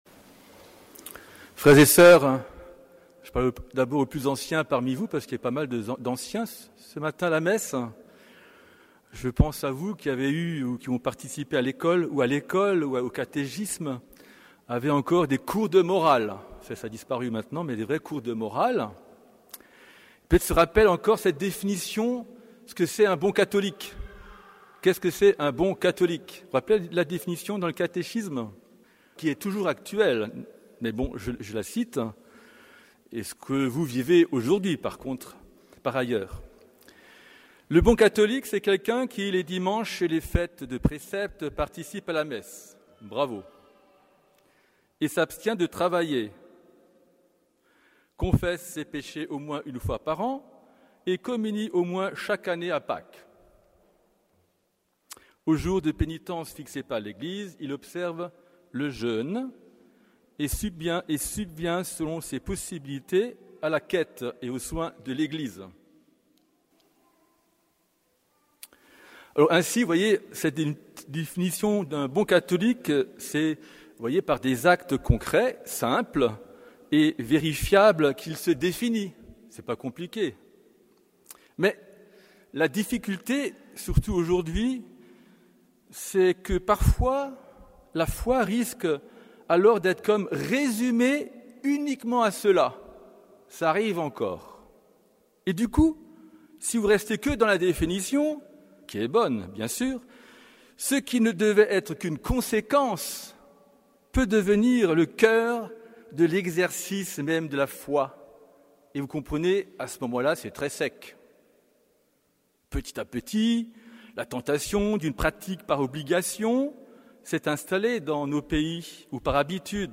Homélie du 23e dimanche du Temps Ordinaire